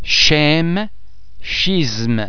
Please be mindful of the fact that all the French sounds are produced with greater facial, throat and other phonatory muscle tension than any English sound.
The French [ch] and [sch] are normally pronounced [sh] as in the English words ash, shot, shy etc.